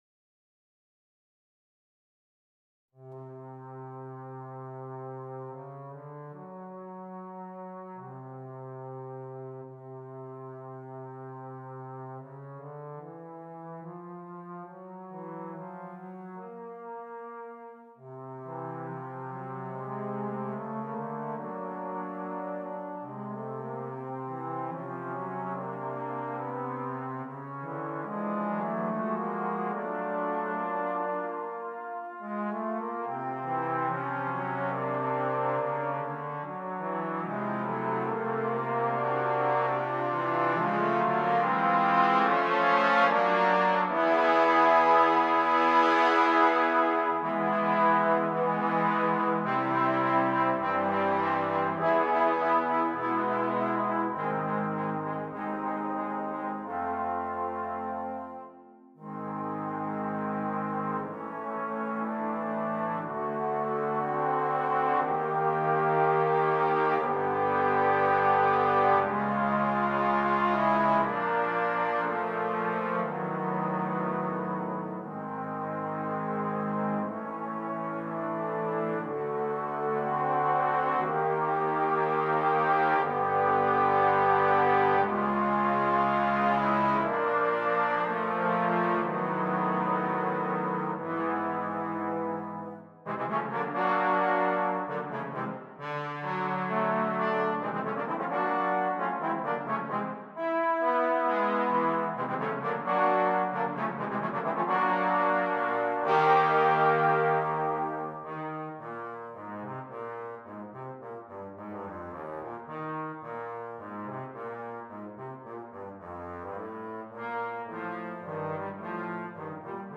4 Trombones
trombone quartet